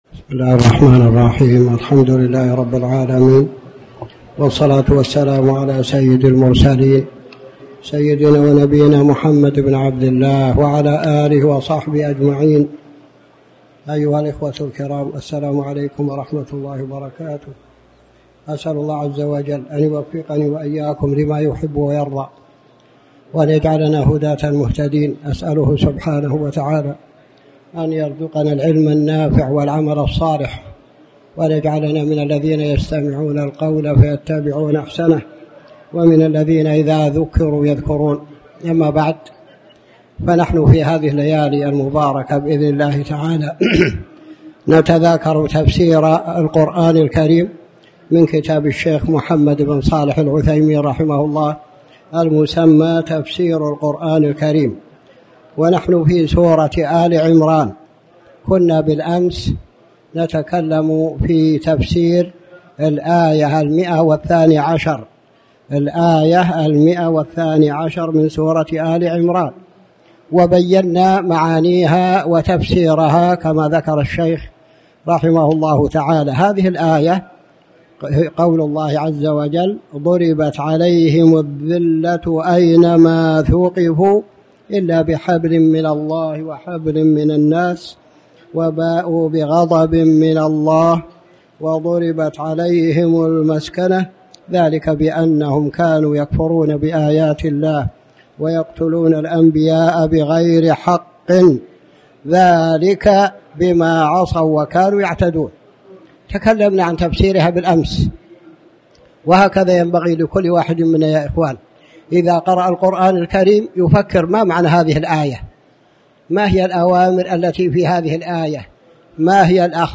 تاريخ النشر ٣٠ ربيع الأول ١٤٣٩ هـ المكان: المسجد الحرام الشيخ